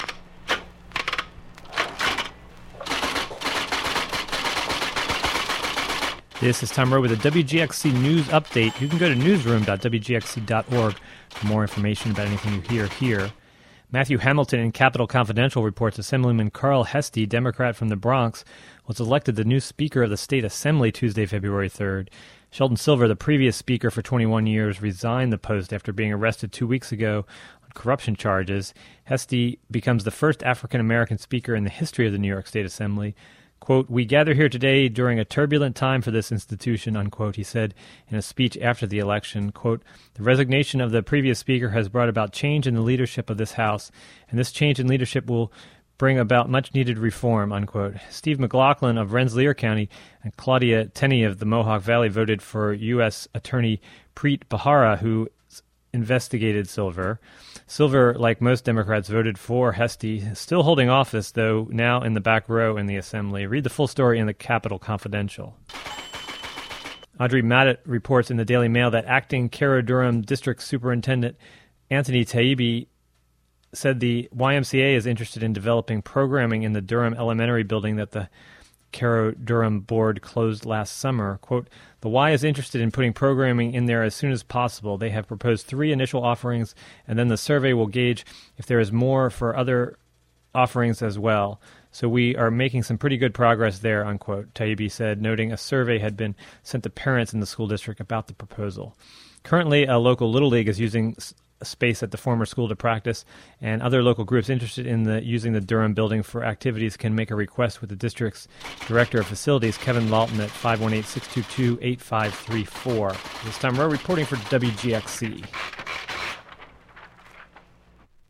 Local news, weather updates, public meeting listin